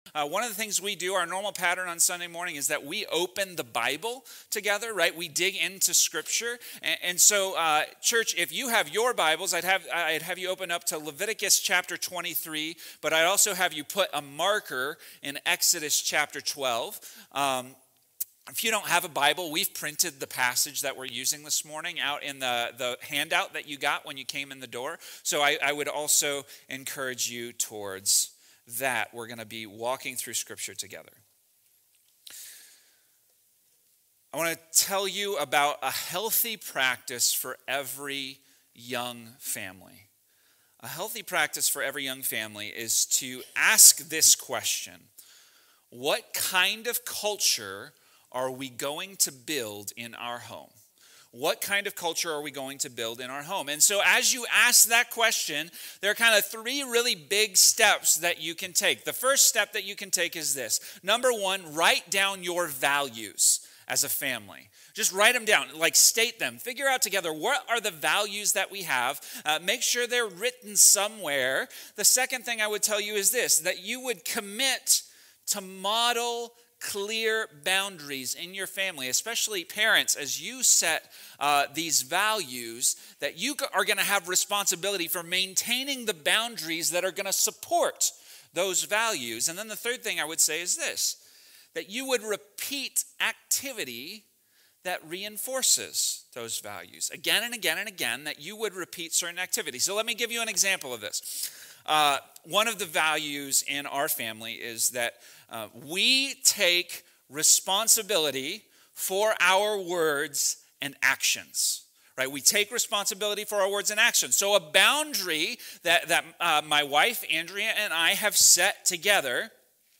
One of the pieces of culture God created were different feasts for different purposes. We take a look at the Passover Feast in this sermon.